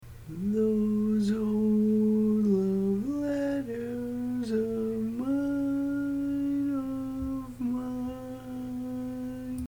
Key written in: F Major
Type: Barbershop
Each recording below is single part only.